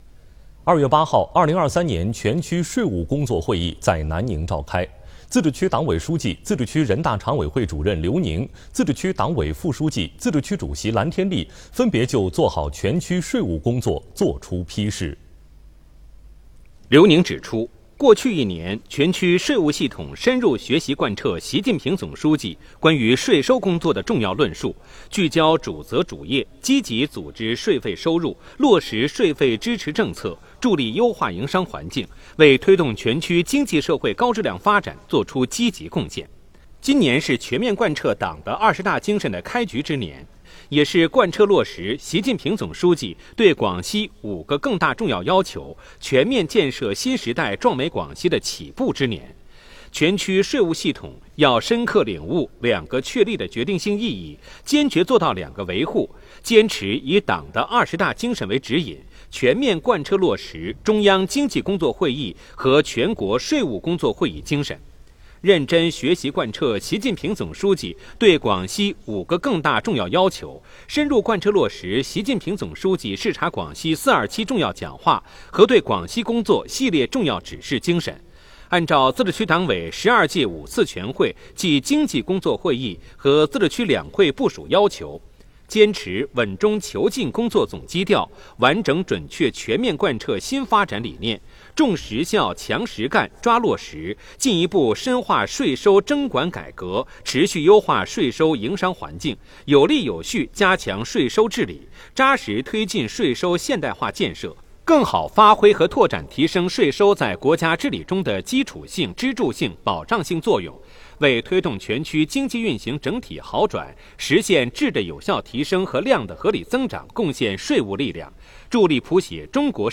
电视报道｜2023年全区税务工作会议召开 刘宁 蓝天立作批示